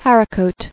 [har·i·cot]